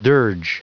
Prononciation du mot dirge en anglais (fichier audio)
Prononciation du mot : dirge